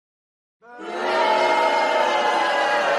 Crowd Boo
Crowd Boo is a free sfx sound effect available for download in MP3 format.
554_crowd_boo.mp3